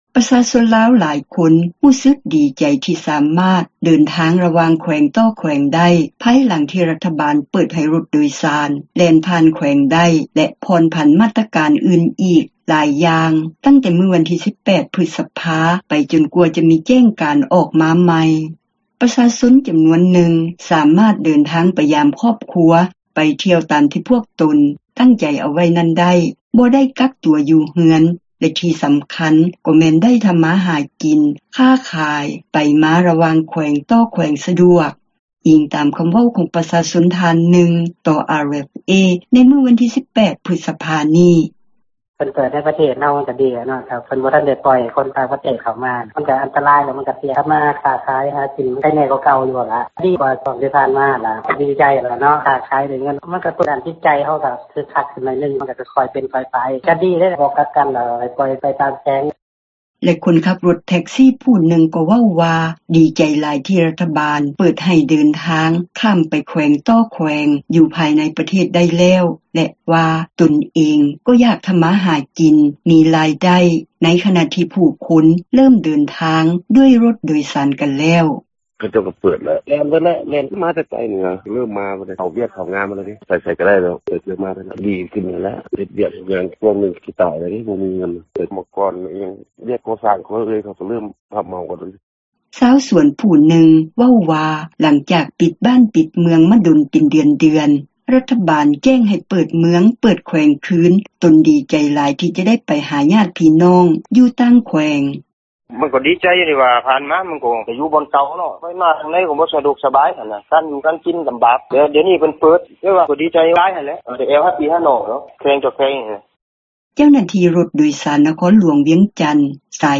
ແລະຄົນຂັບຣົດແທັກຊີຜູ້ນຶ່ງ ກໍເວົ້າວ່າດີໃຈຫຼາຍ ທີ່ຣັຖບານເປີດໃຫ້ ເດີນທາງຂ້າມໄປແຂວງຕໍ່ແຂວງ ຢູ່ພາຍໃນປະເທດໄດ້ ແລະວ່າຕົນເອງ ກໍຢາກທໍາມາຫາກິນ ມີຣາຍໄດ້ ໃນຂນະທີ່ຜູ້ຄົນເລີ່ມເດີນທາງ ດ້ວຍຣົດໂດຍສານກັນແລ້ວ:
ຊາວສວນຜູ້ນຶ່ງ ເວົ້າວ່າ ຫລັງຈາກປິດບ້ານ ປິດເມືອງມາດົນເປັນເດືອນໆ ຣັຖບານແຈ້ງໃຫ້ເປີດເມືອງ ເປີດແຂວງຄືນ ຕົນດີໃຈຫຼາຍ ທີ່ຈະ ໄດ້ໄປຫາຍາດພີ່ນ້ອງ ຢູ່ຕ່າງແຂວງ: